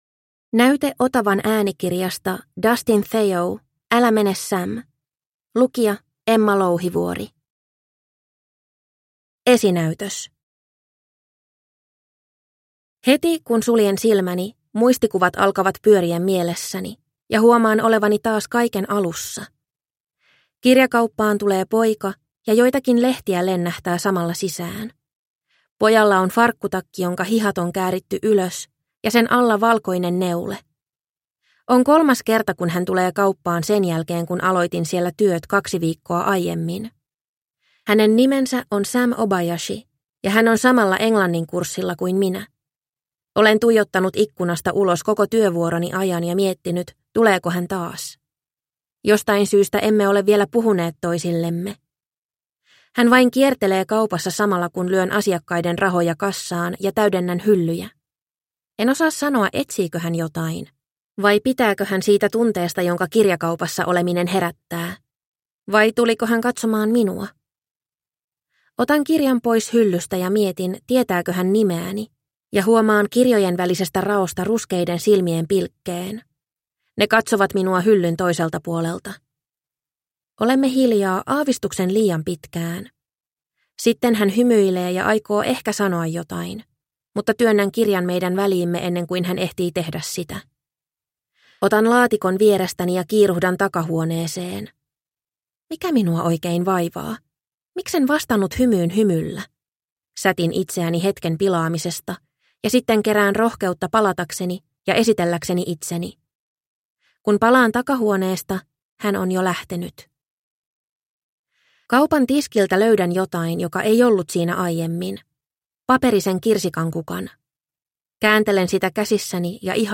Älä mene, Sam – Ljudbok – Laddas ner